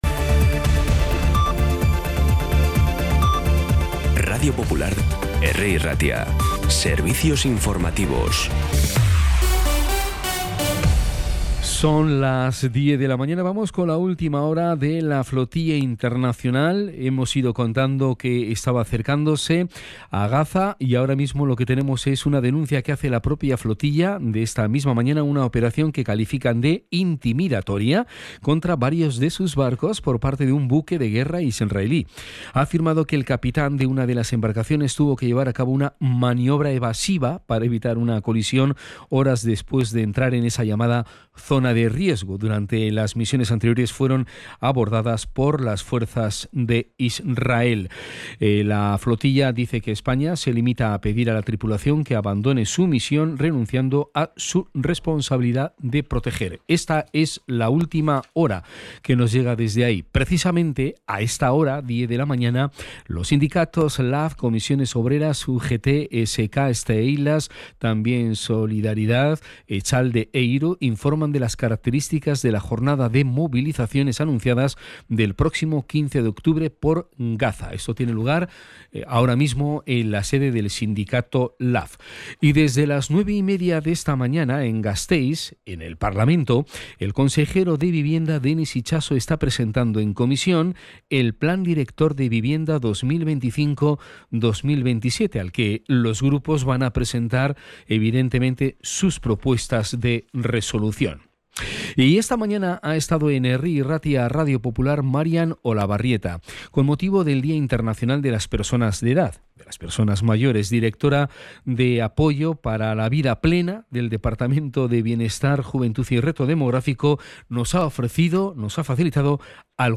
Las noticias de Bilbao y Bizkaia del 1 de octubre a las 10
Los titulares actualizados con las voces del día.